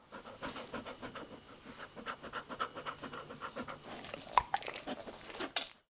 动物呼吸的狗
描述：手风琴的声音
标签： 动物 呼吸
声道立体声